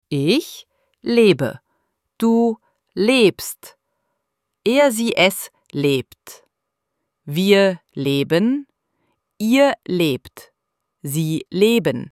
IZGOVOR – LEBEN:
ElevenLabs_Text_to_Speech_audio-65.mp3